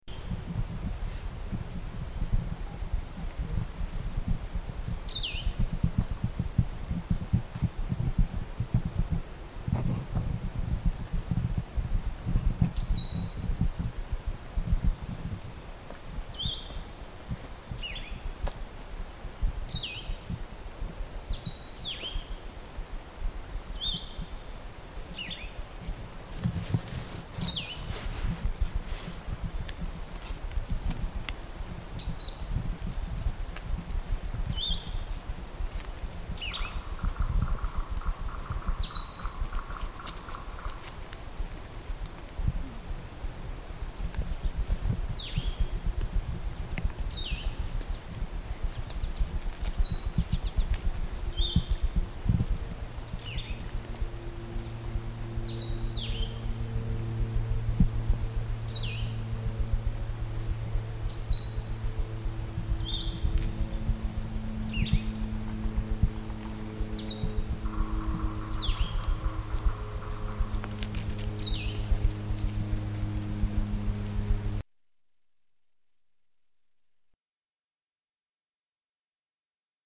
Here's a Vireo singing.  It is either a Red-eyed Vireo or a Blue-headed Vireo.
vireo.mp3